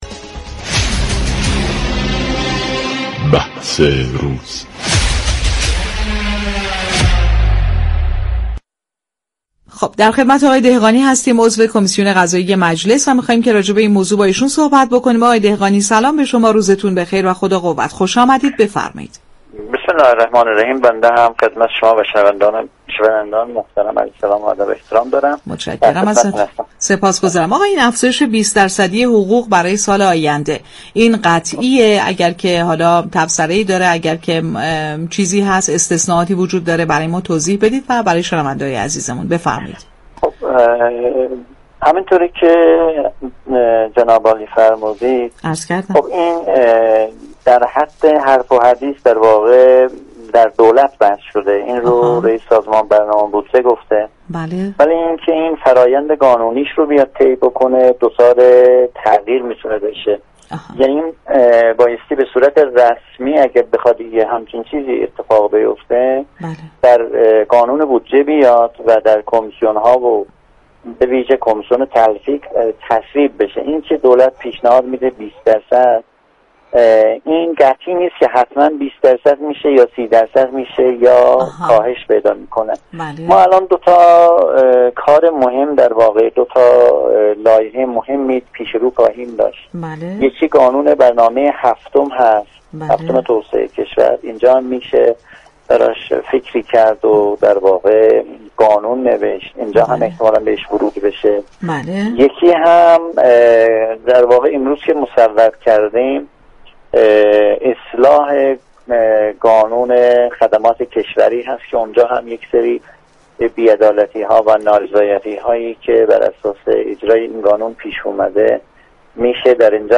به گزارش پایگاه اطلاع رسانی رادیو تهران؛ اله‌ویردی دهقانی عضو كمیسیون صنایع و معادن مجلس شورای اسلامی در گفت و گو با "بازار تهران" رادیو تهران درخصوص خبر منتشر شده مبنی بر افزایش 20 درصدی حقوق كاركنان دولت در سال 1402 این گونه اظهار داشت: افزایش 20 درصدی حقوق كاركنان برای سال آینده از سوی رییس سازمان برنامه و بودجه كشور اعلام شده است و این خبر قطعی نیست.